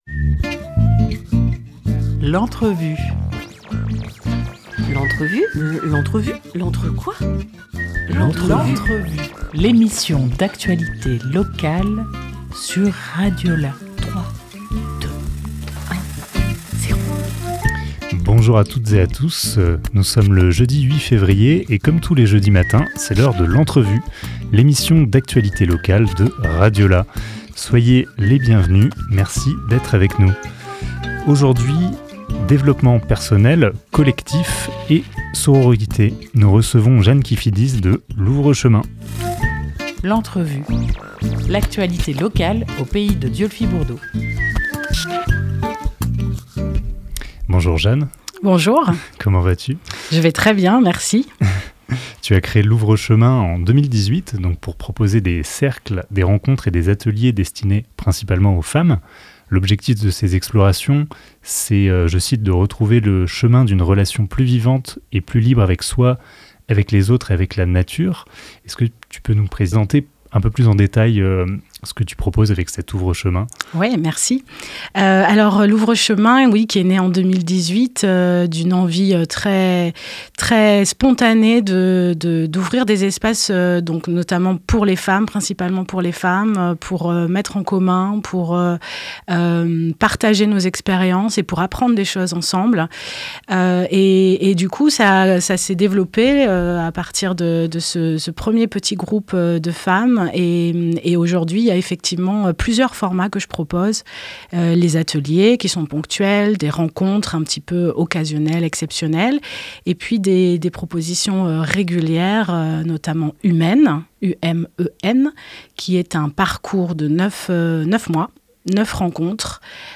8 février 2024 11:45 | Interview